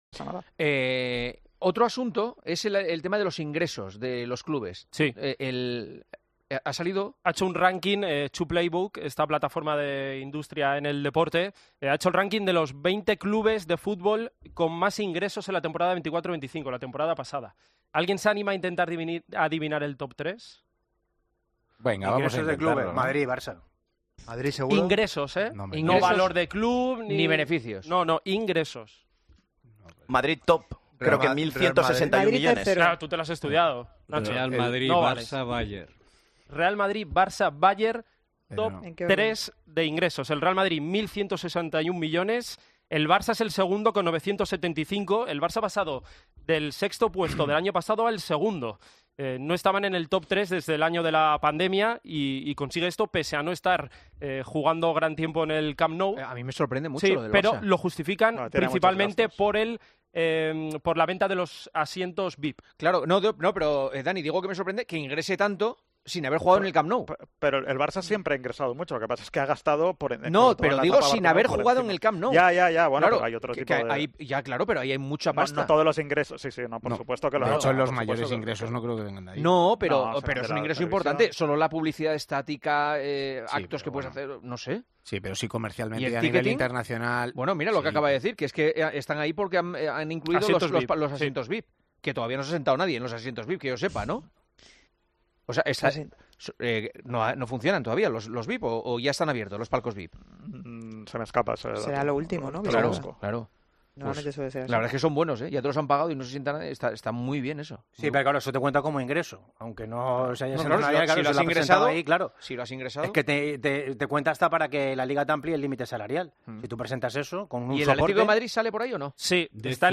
El Partidazo de COPE abre debate sobre el ránking de ingresos de los clubes de fútbol